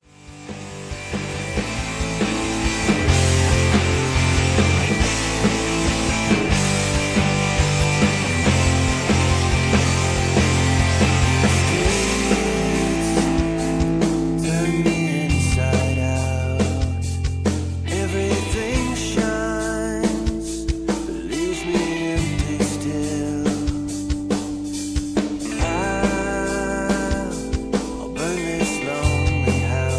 Tags: karaoke , backing tracks , soundtracks